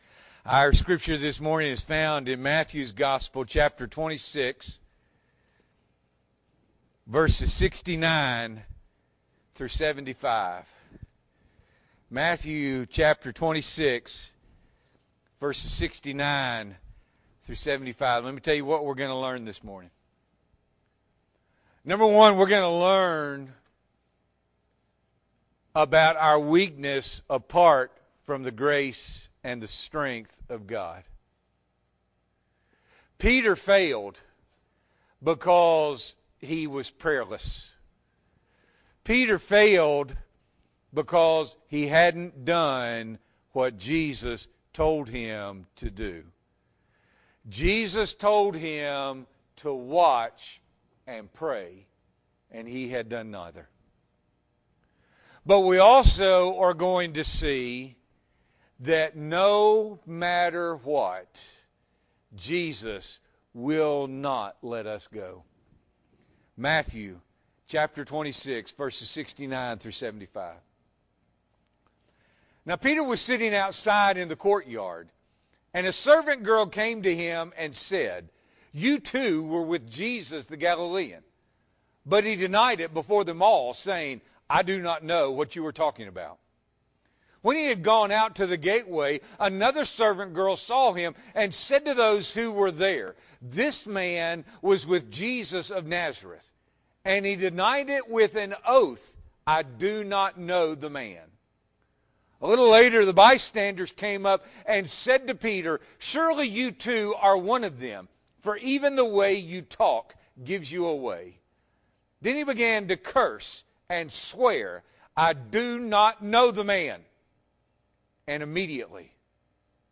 February 21, 2021 – Morning Worship